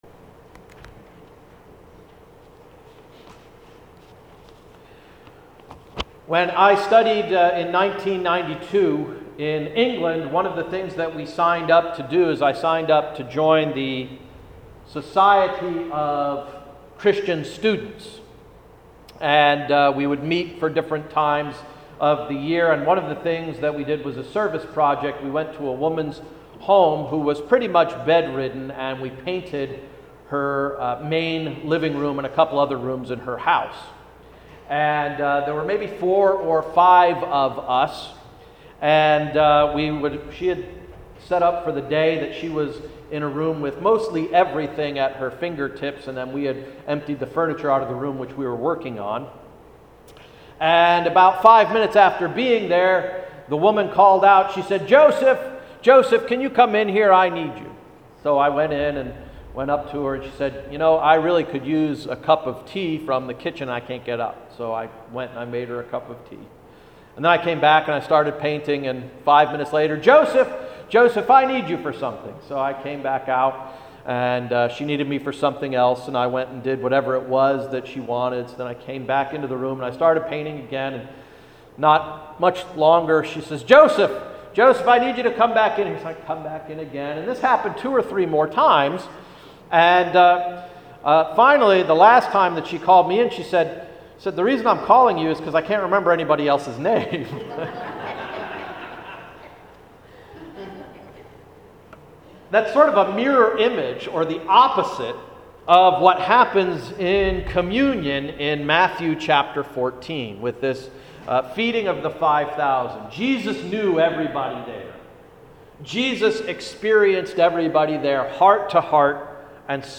Sermon of August 3, 2014–“White, Wheat, or Rye?”